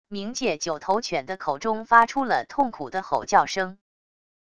冥界九头犬的口中发出了痛苦的吼叫声wav音频